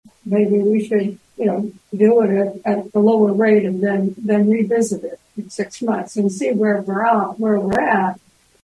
Commissioner Kathryn Focke suggested a smaller change.